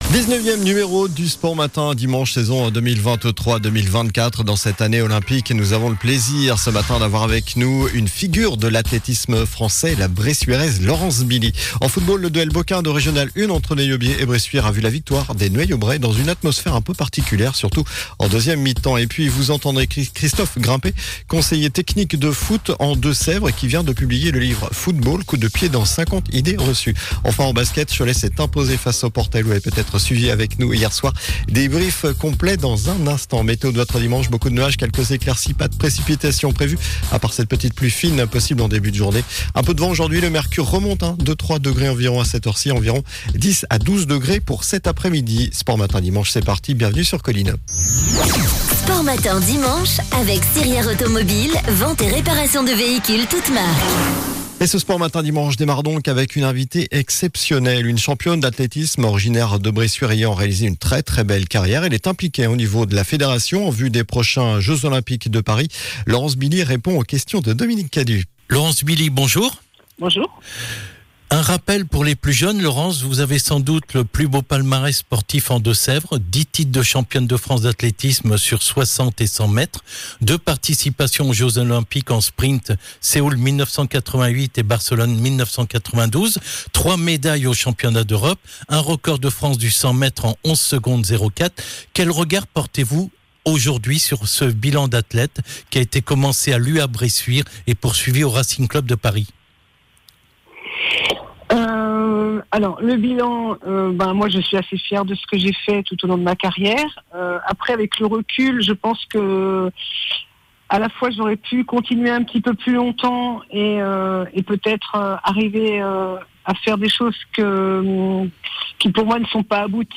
COLLINES LA RADIO : Réécoutez les flash infos et les différentes chroniques de votre radio⬦